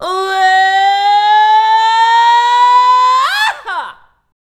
SCREAM.wav